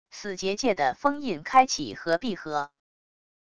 死结界的封印开启和闭合wav音频